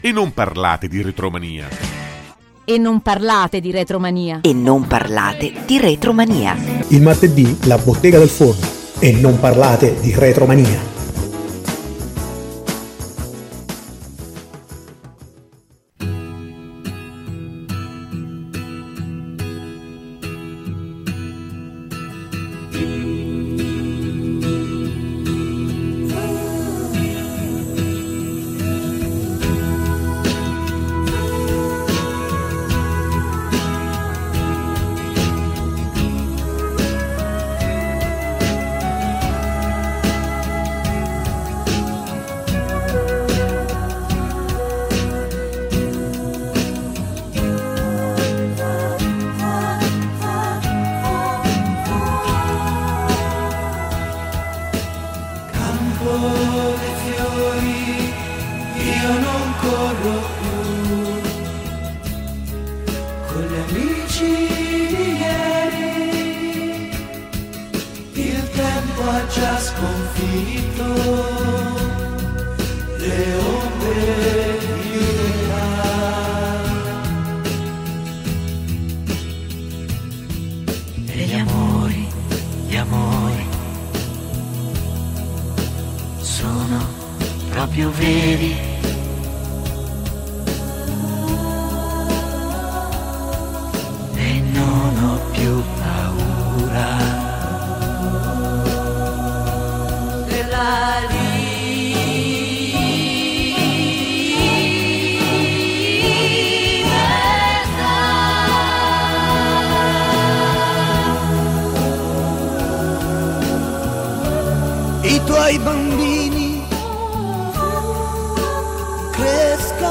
Intervista-Edoardo-De-Angelis.mp3